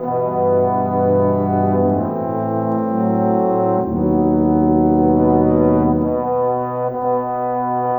Cinematic 27 Horns 04.wav